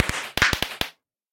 should be correct audio levels.
twinkle1.ogg